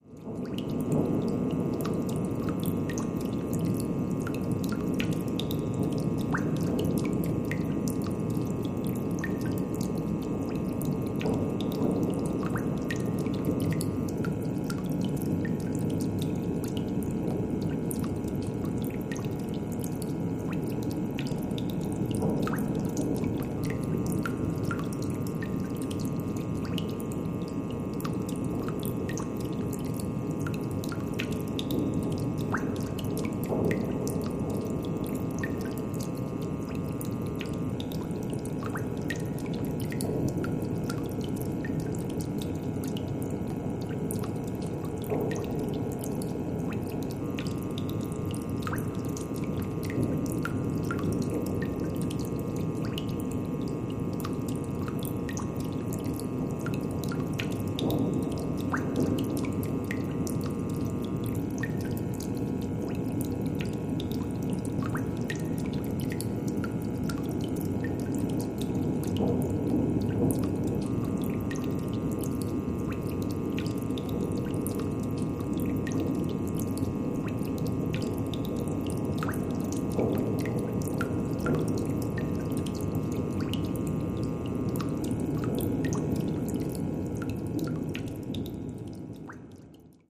Basement Ambiance